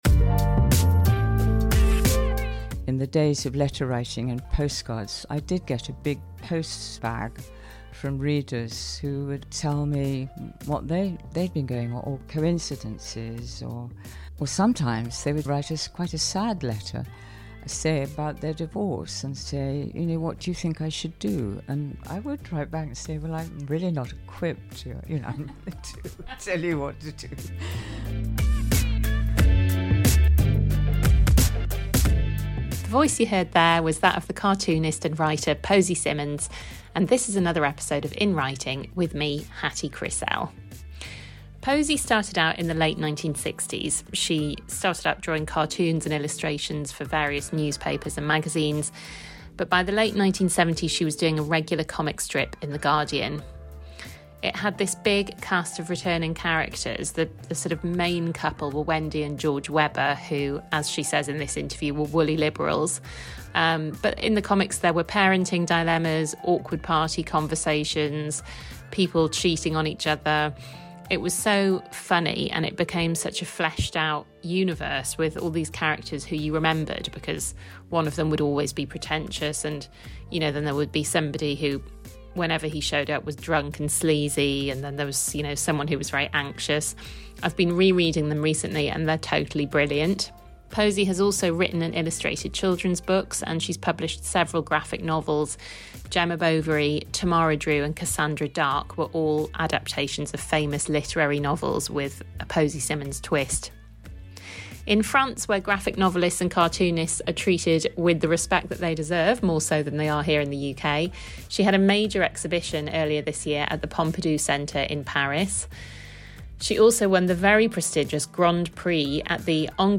This week I'm in the London home of artist and writer Posy Simmonds. From the 1970s onwards, Posy had a regular comic strip in The Guardian, where she wrote Mrs Weber's Diary and later serialised the graphic novel Tamara Drewe. She went on to publish two more graphic novels, Gemma Bovery and Cassandra Darke.